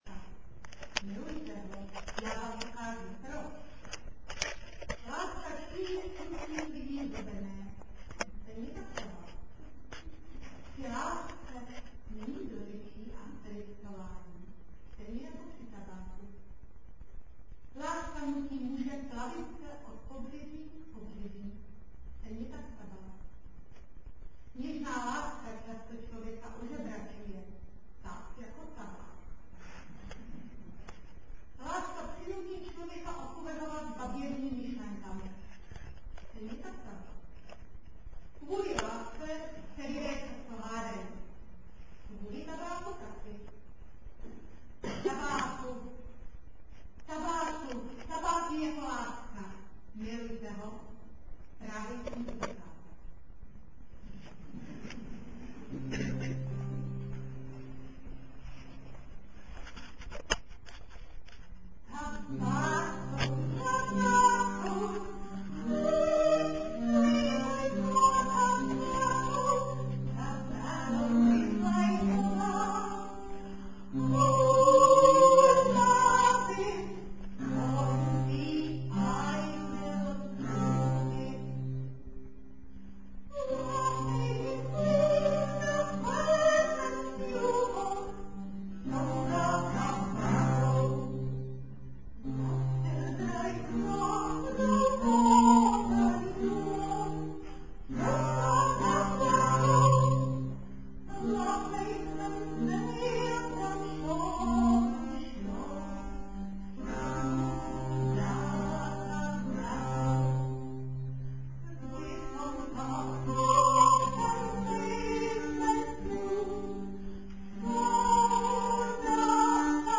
17. komorní koncert na radnici v Modřicích
Anglická, italská a španělská renesance - rozverné zpěvy, loutna a viola da gamba - tak nějak se bavili lidé před 400 lety ...
Amatérský záznam, jako ukázka skladeb z koncertu - formát .WMA: